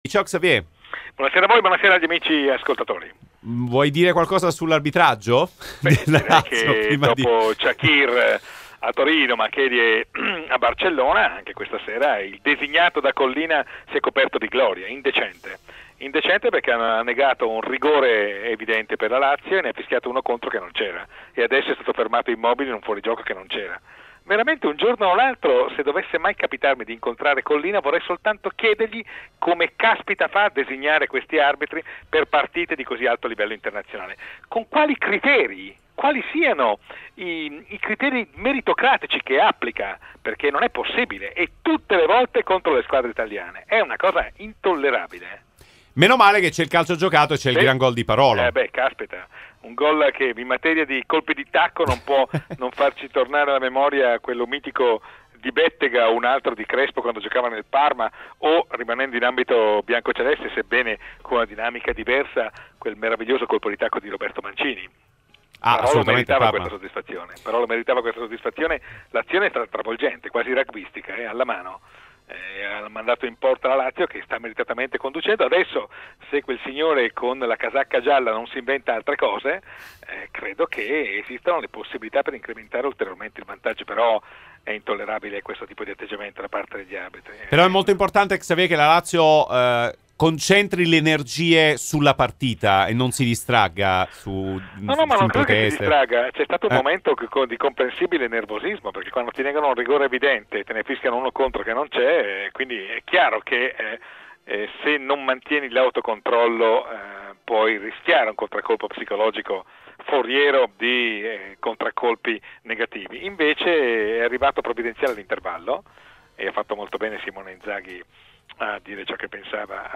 all'interno del Live Show serale ha commentato così l'arbitraggio della sfida di Europa League tra Lazio e Salisburgo